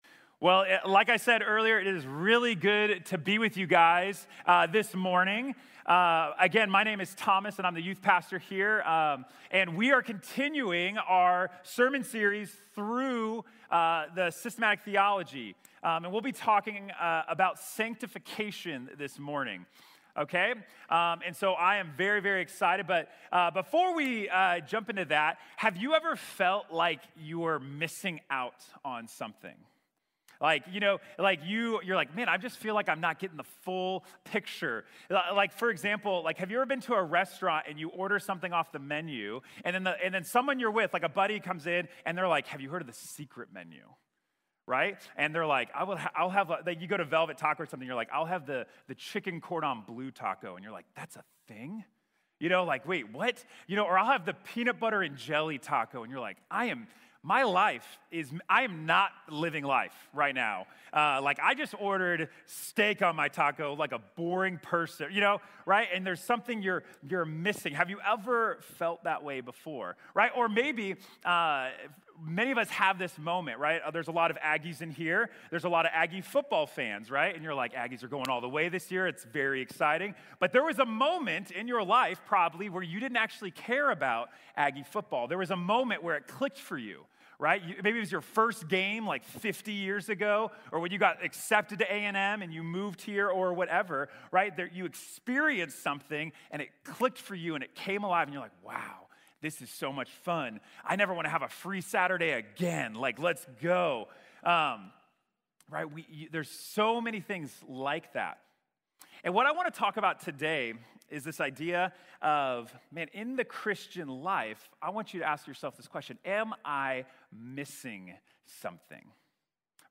Santificación | Sermón | Iglesia Bíblica de la Gracia